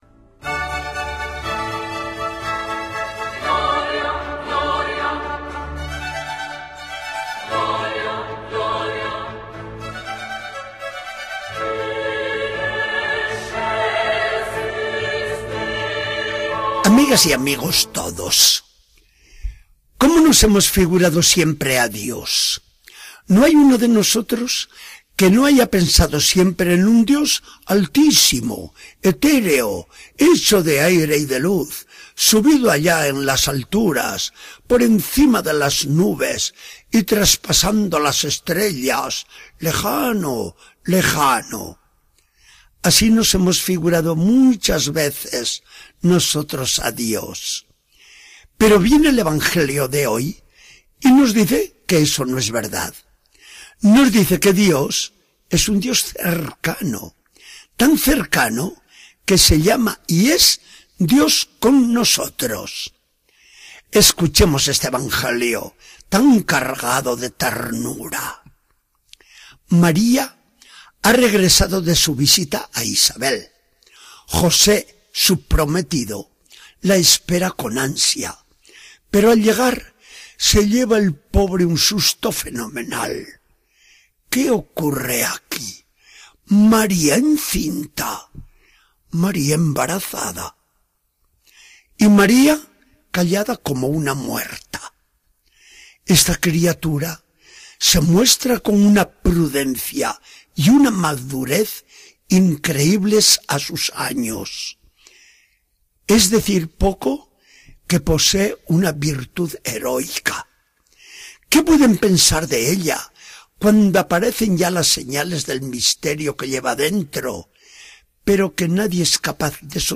Charla del día 22 de diciembre de 2013. Del Evangelio según San Mateo.